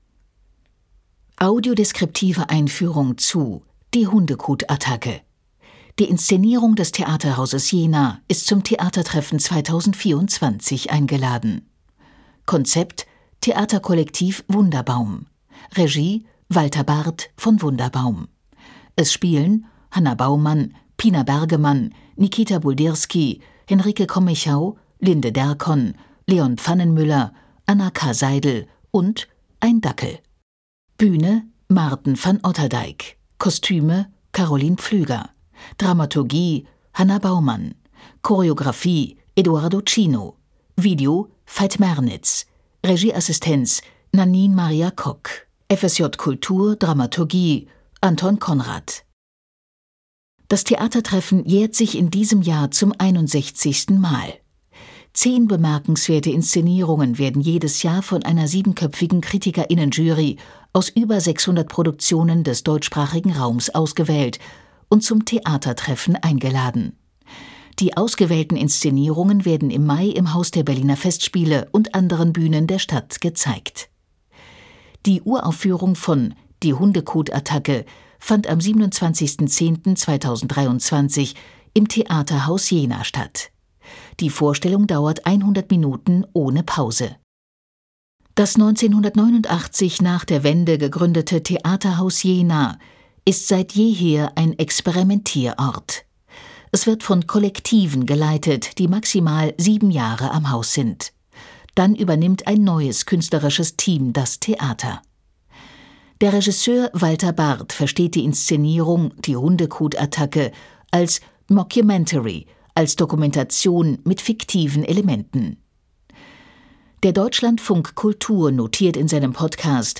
Audiodeskription „Die Hundekot-Attacke”
tt24_audiodeskription_hundekot-attacke.mp3